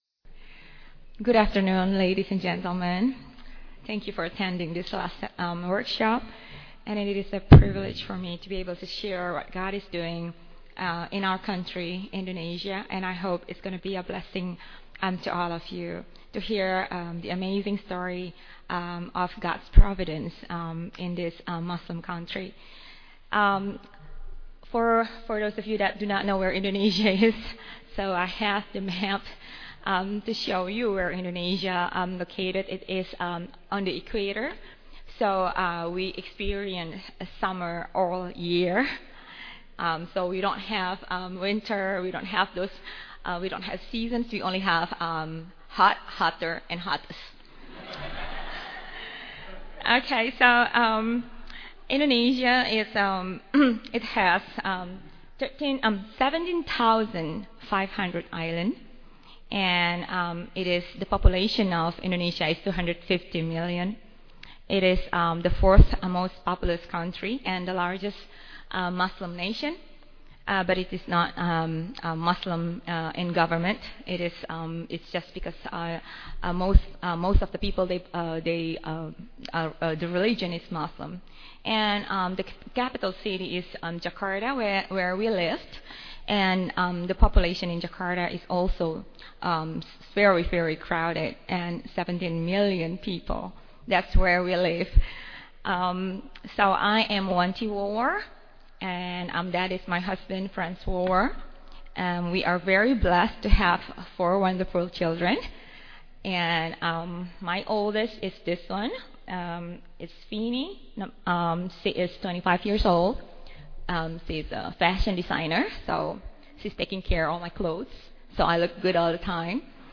2009 Foundations Talk | 1:10:43 | All Grade Levels, Culture & Faith
Mar 11, 2019 | All Grade Levels, Conference Talks, Culture & Faith, Foundations Talk, Library, Media_Audio | 0 comments
The Association of Classical & Christian Schools presents Repairing the Ruins, the ACCS annual conference, copyright ACCS.